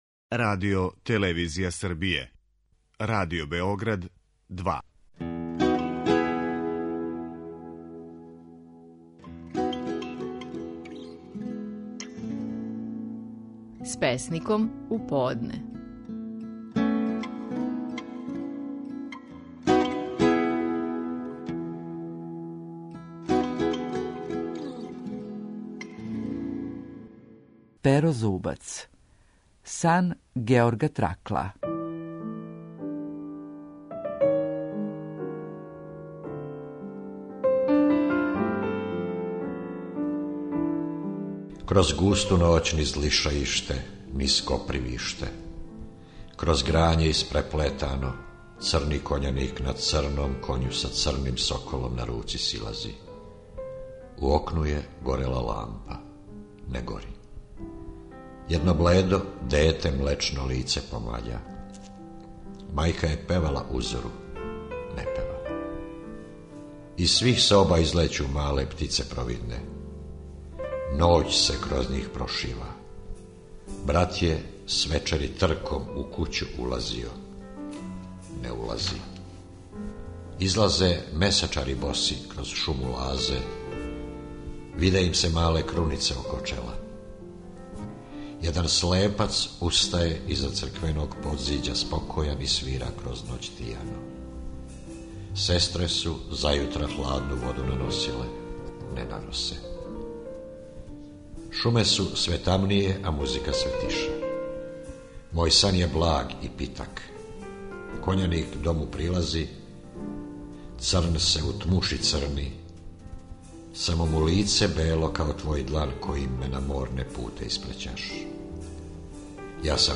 Стихови наших најпознатијих песника, у интерпретацији аутора.
Перо Зубац говори стихове песме „Сан Георга Тракла".